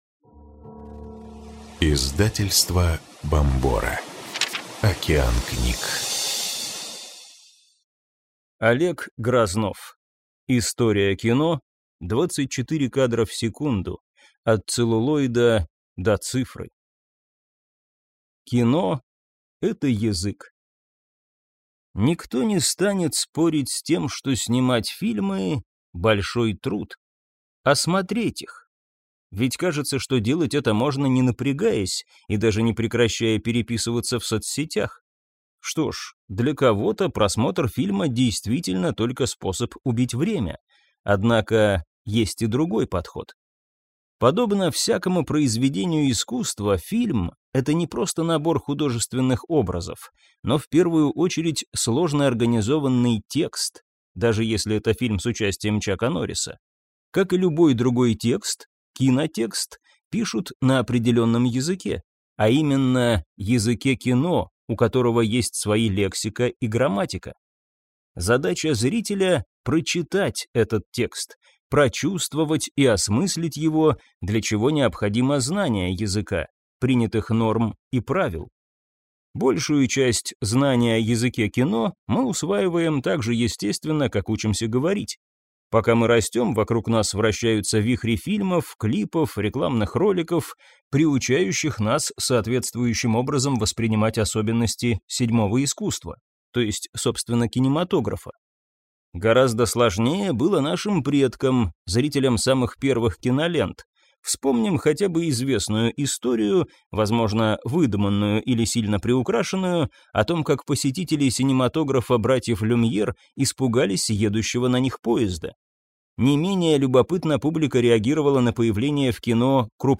Аудиокнига История кино. 24 кадра в секунду. От целлулоида до цифры | Библиотека аудиокниг